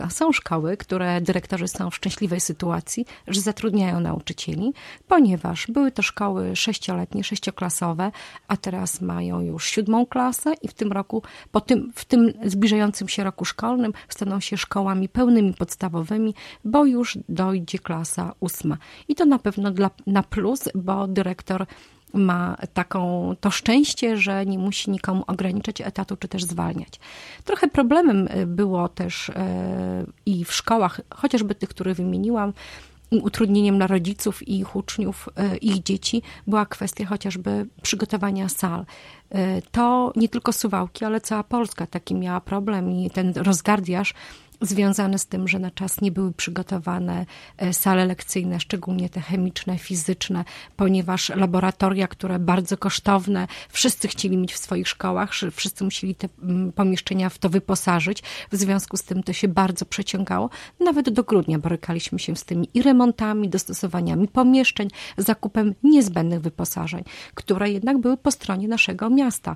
W związku z tym we wtorek (19.06) na antenie Radia 5 Ewa Sidorek, zastępca prezydenta Suwałk, podsumowywała mijający rok szkolny. Jak mówiła, to był czas wdrażania reformy oświaty.